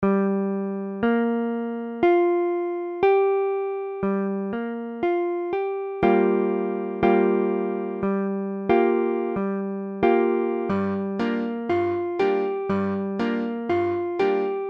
Gm7 : accord de Sol mineur septi�me Mesure : 4/4
Tempo : 1/4=60
III_Gm7.mp3